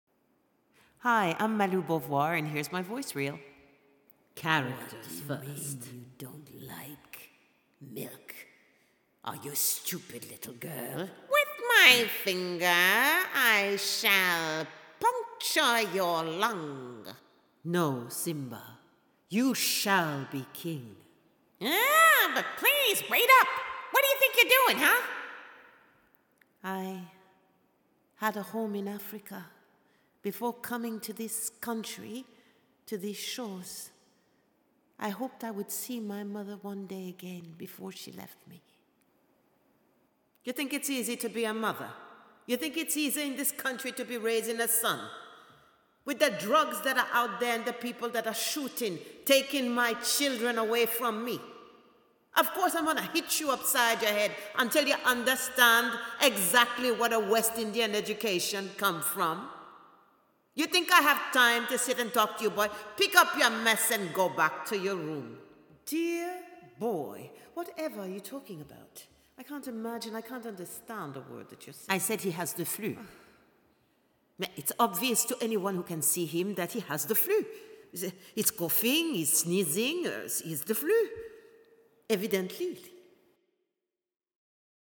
Bandes-son